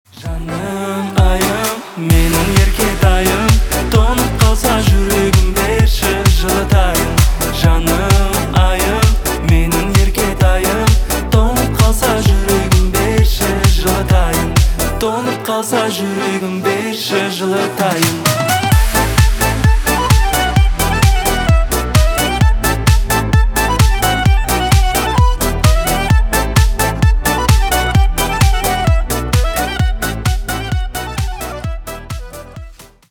восточные на казахском